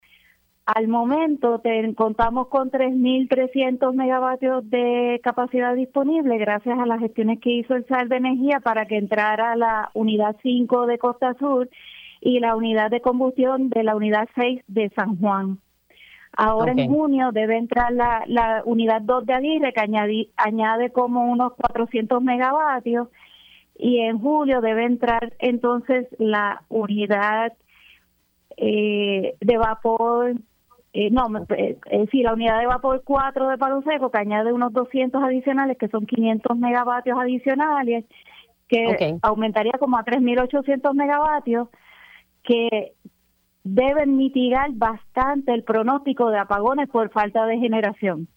La directora ejecutiva se mantuvo firme que podrán cumplir con las demandas energéticas en el verano caluroso que se avecina.